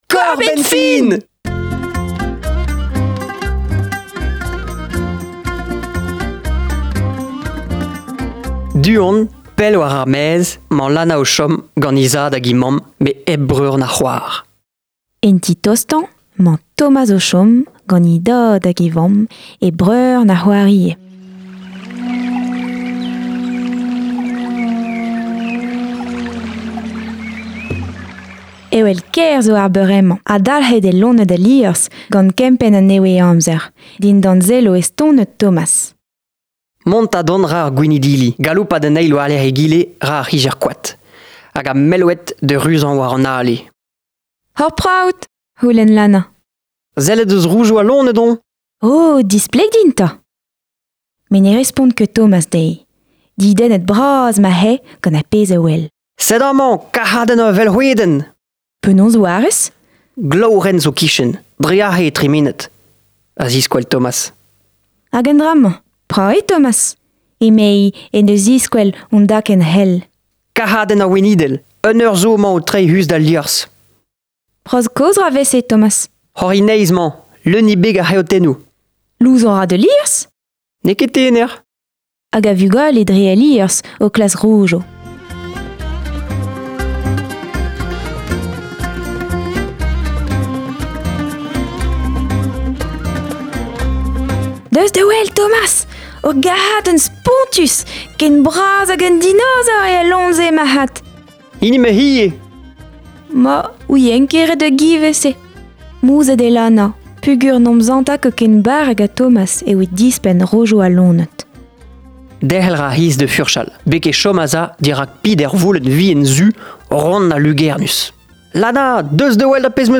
Enrollet gant Radio Kreiz Breizh